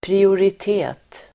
Ladda ner uttalet
Uttal: [priorit'e:t]